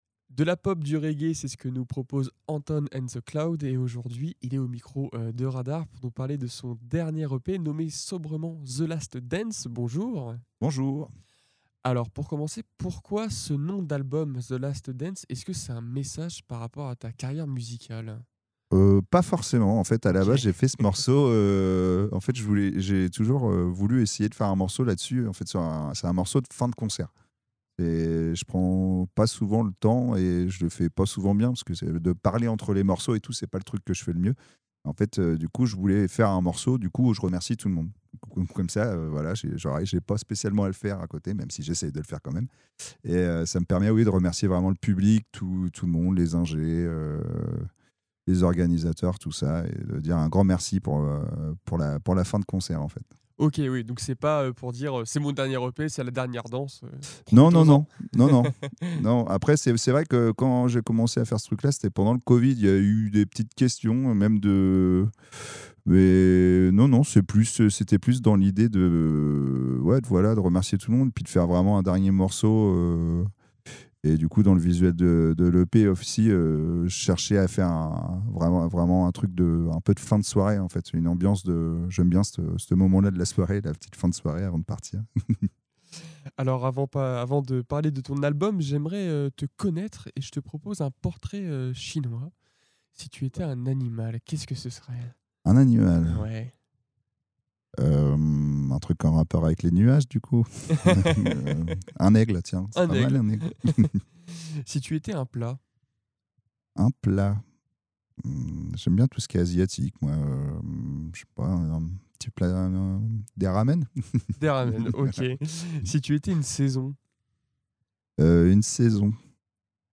Actu, sport, vie associative et bien plus encore : ici, vous retrouverez toutes les interviews produites par RADAR 🎙
Les interviews Radar Actu Interview fécamp podcast